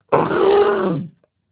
shadowkeyalert.amr